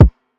Beholder Kick.wav